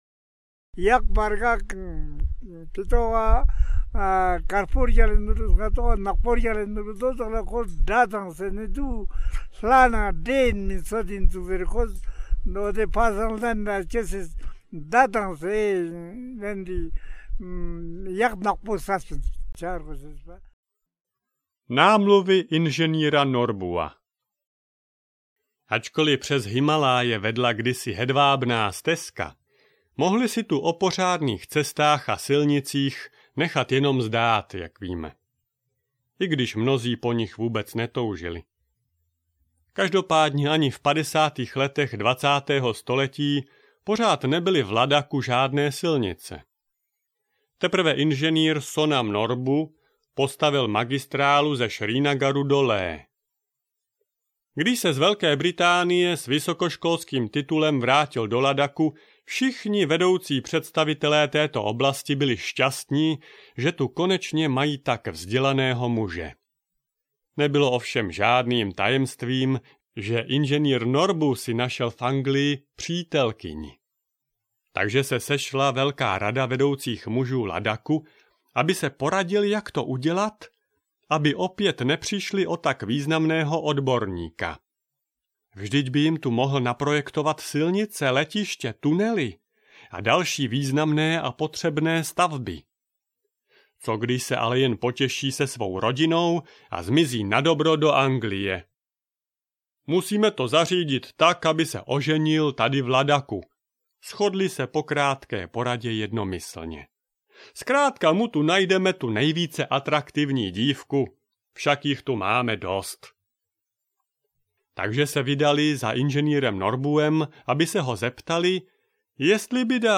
Příběhy Malého Tibetu audiokniha
Ukázka z knihy